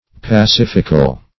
pacifical - definition of pacifical - synonyms, pronunciation, spelling from Free Dictionary
Search Result for " pacifical" : The Collaborative International Dictionary of English v.0.48: pacifical \pa*cif"ic*al\, a. Of or pertaining to peace; pacific.